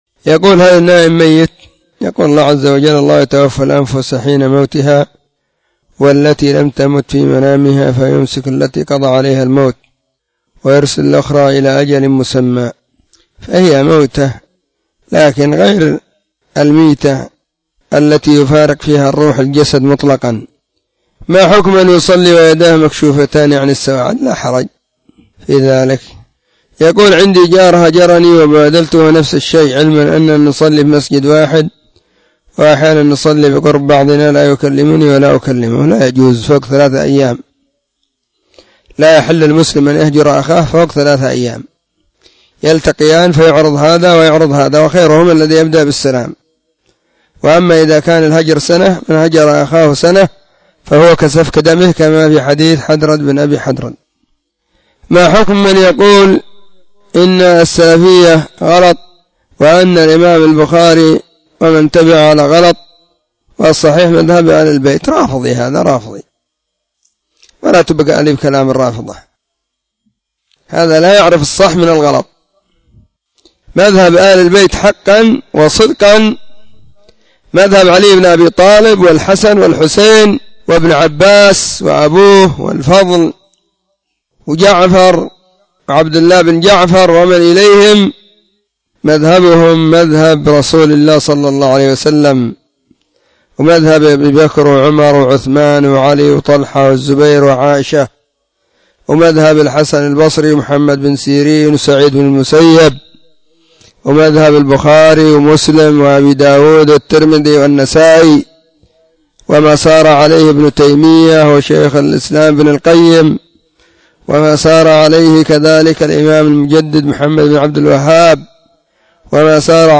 فتاوى, الإثنين 20 /صفر/ 1443 هجرية,.